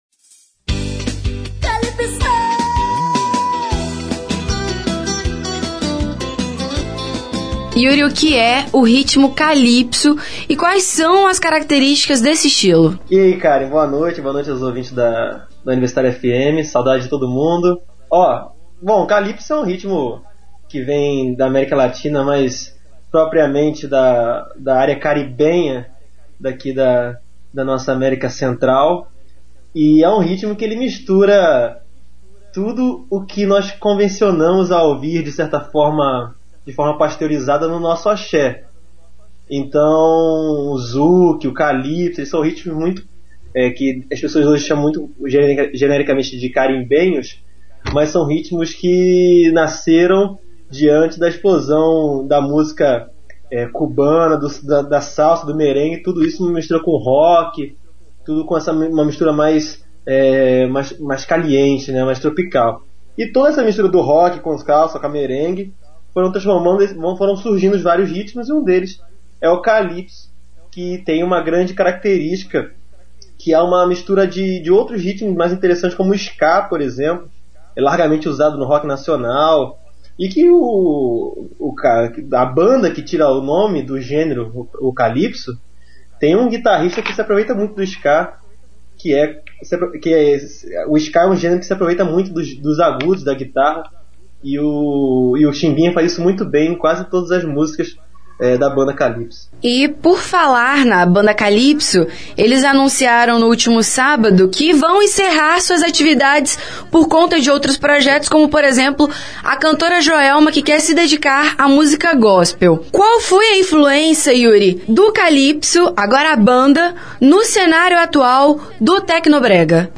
Para homenagear os precursores de um dos estilos musicais mais quentes do Brasil, o tecnobrega, o Revista Universitária preparou uma matéria sobre a nova música brasileira e suas regionalidades. Confira a entrevista completa